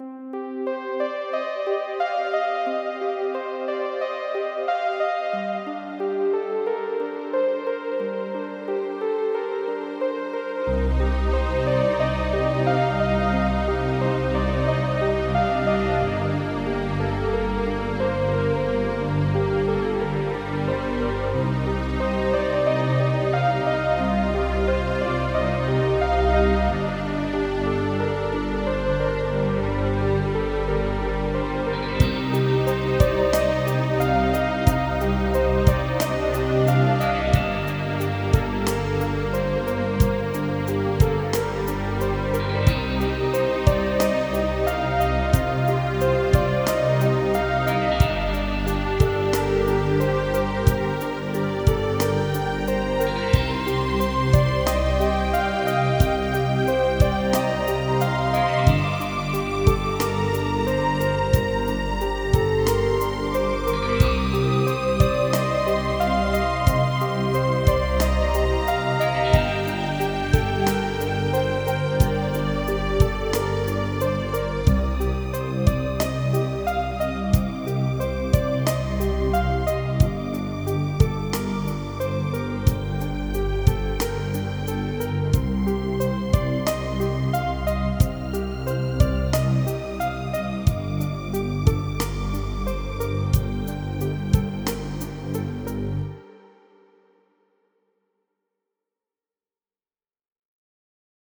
Dystopian